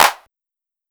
Clap (Miss Me).wav